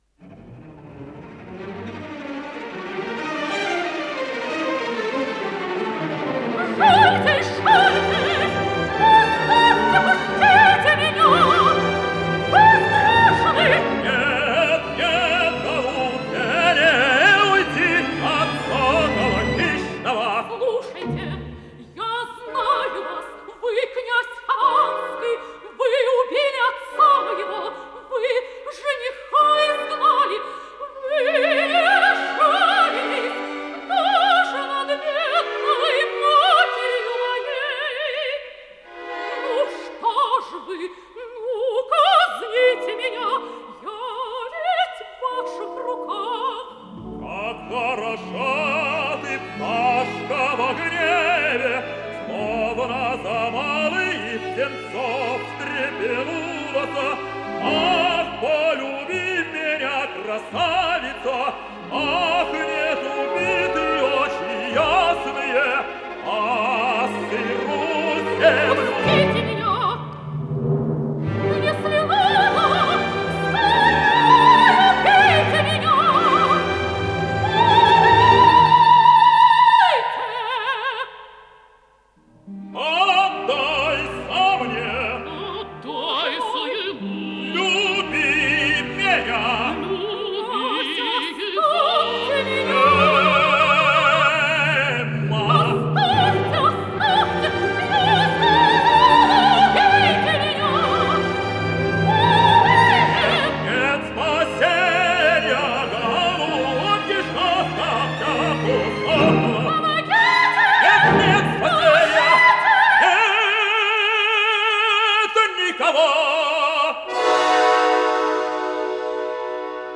Исполнитель: Солисты, хор и оркестр Государственного академического Большого театра СССР
Название передачи Хованщина Подзаголовок Народная музыкальная драма в 5-ти действиях, 6-ти картинах Код ПКС-025655 Фонд Без фонда (ГДРЗ) Редакция Музыкальная Общее звучание 02:42:21 Дата записи 17.10.1988 Дата добавления 13.10.2024 Прослушать